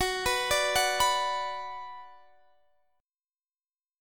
Listen to Gbsus4#5 strummed